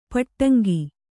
♪ paṭṭaŋgi